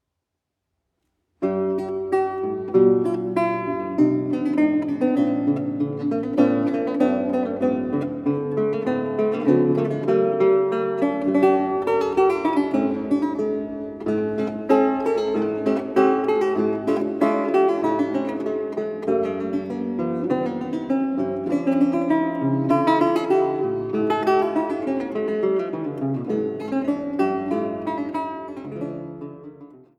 Laute
Bearbeitung für Laute